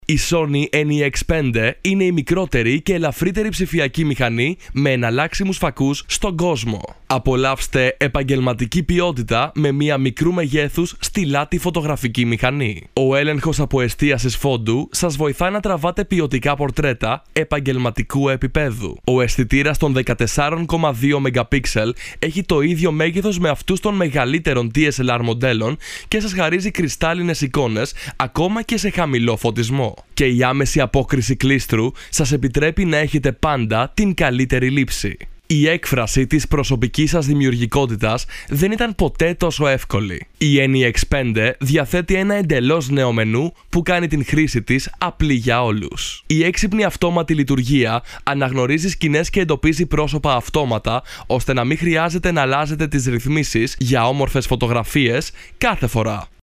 Experienced, well known Greek radio presenter and versatile voice over talent suitable for any kind of voice over recording in Greek small or large.
Kein Dialekt
Sprechprobe: Werbung (Muttersprache):